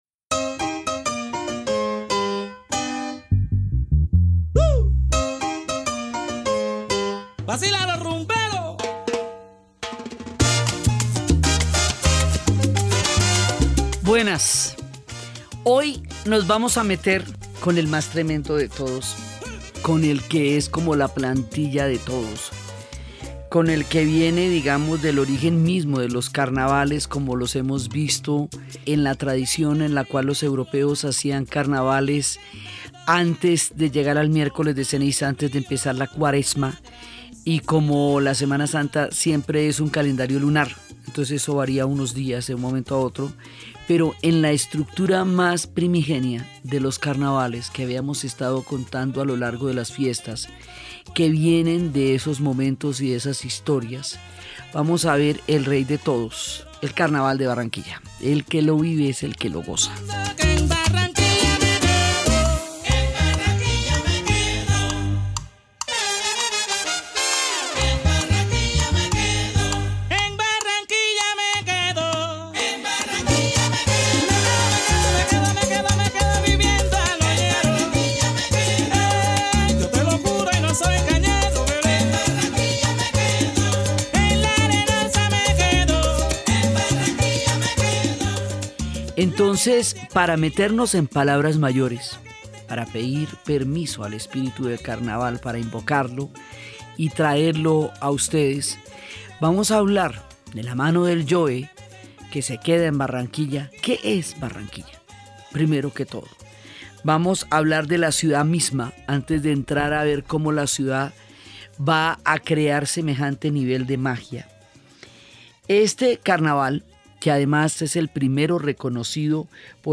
Descúbrelo en la voz de Diana Uribe ingresando gratis a la plataforma de RTVCPlay.